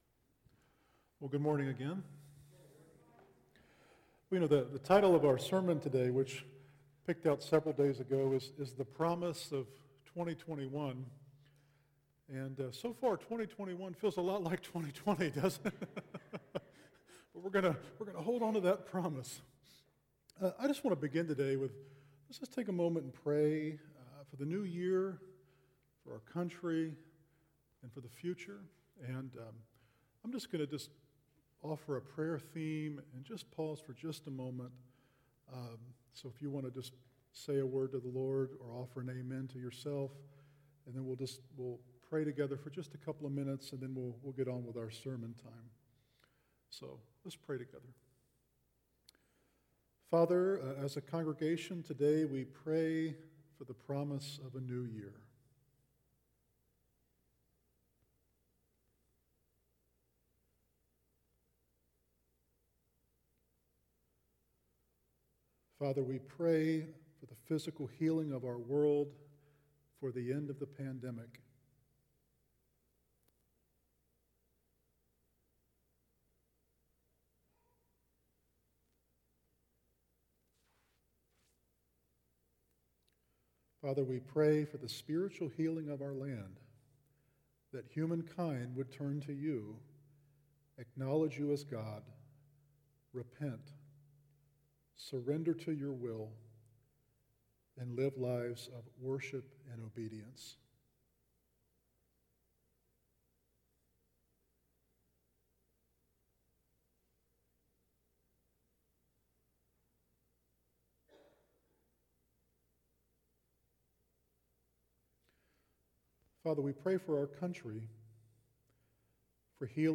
A Discussion with RCC’s Elders